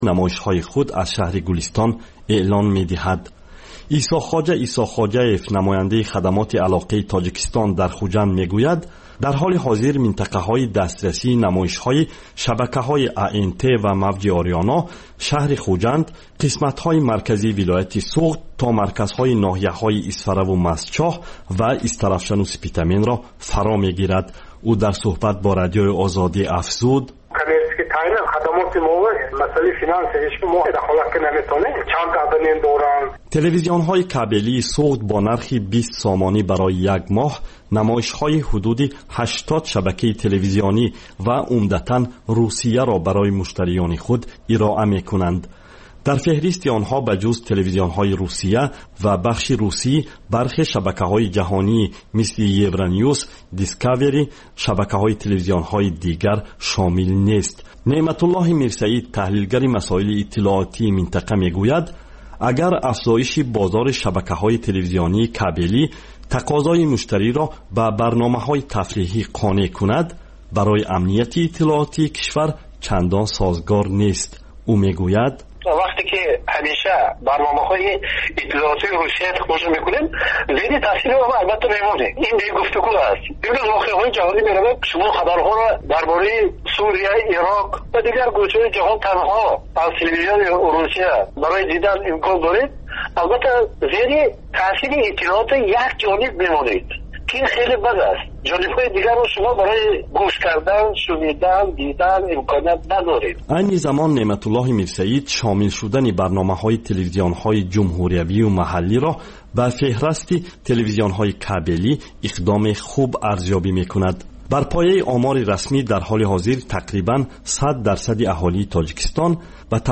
Гуфтори вижаи Радиои Озодӣ аз ҳаёти ҷавонони Тоҷикистон ва хориҷ аз он